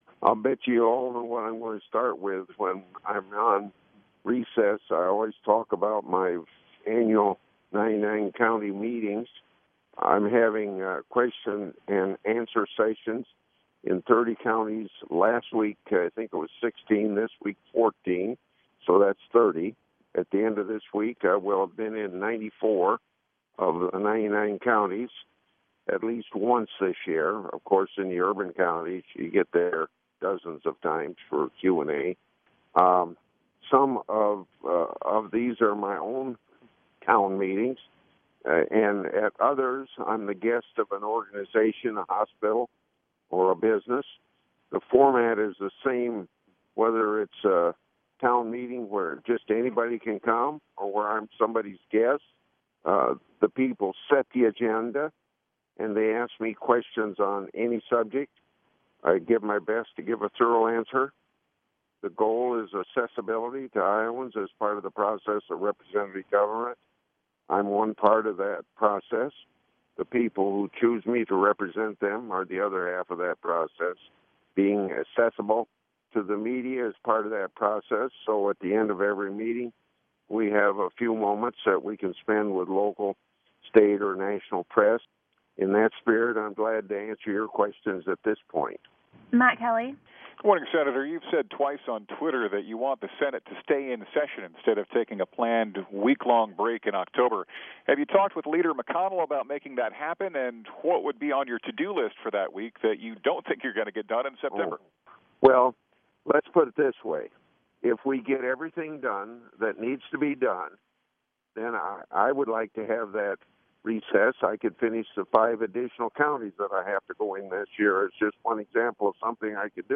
Radio Networks Call